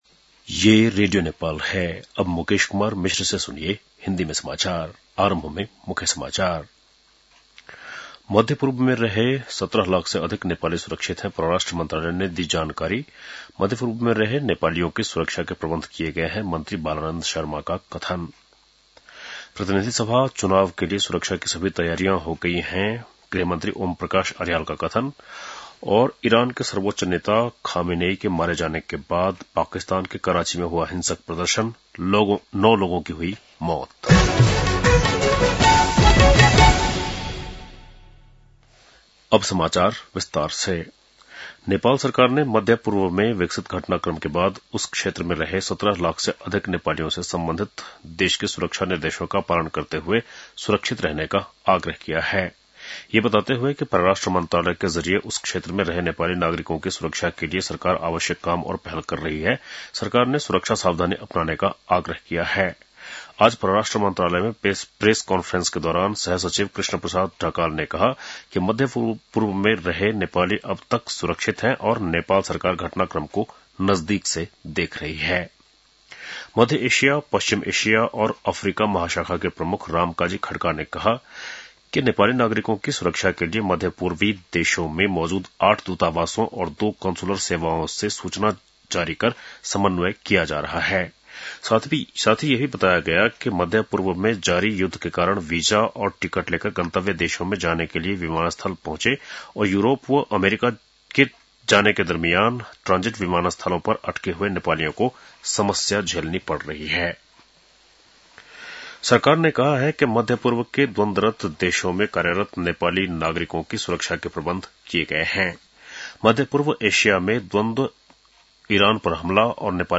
बेलुकी १० बजेको हिन्दी समाचार : १७ फागुन , २०८२